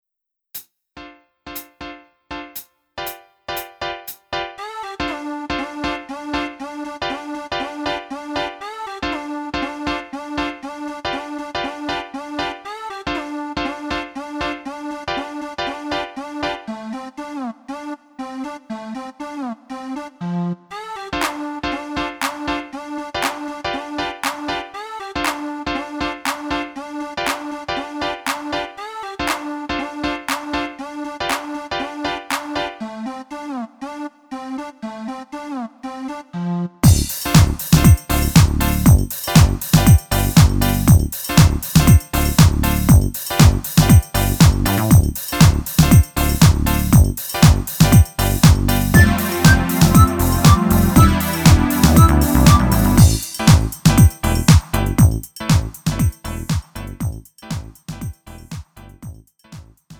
음정 -1키 3:39
장르 가요 구분 Lite MR